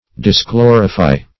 Search Result for " disglorify" : The Collaborative International Dictionary of English v.0.48: Disglorify \Dis*glo"ri*fy\, v. t. [imp.